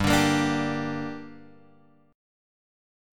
Gm chord {3 x 0 3 3 3} chord
G-Minor-G-3,x,0,3,3,3.m4a